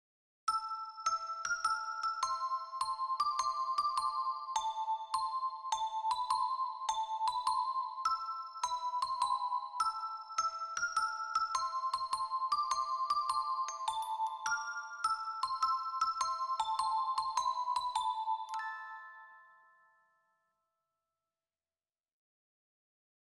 Dark arrange version of famous works